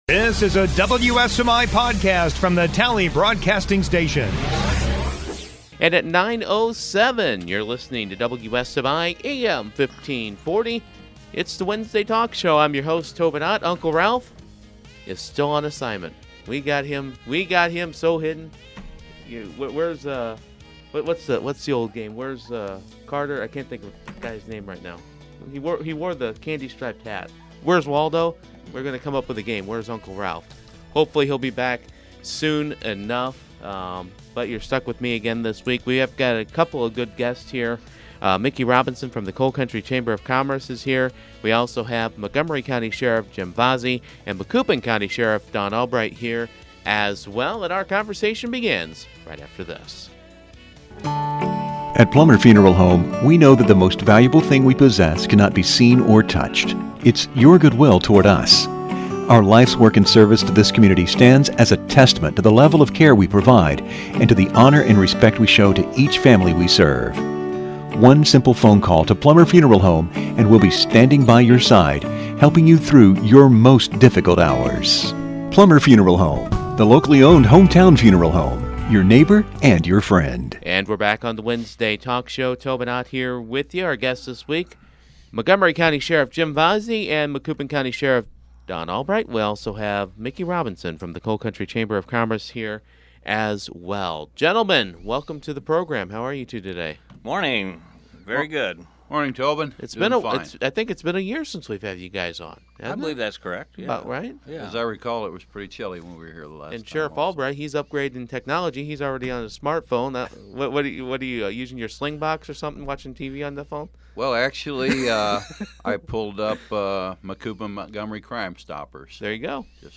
Wednesday Morning Talk Show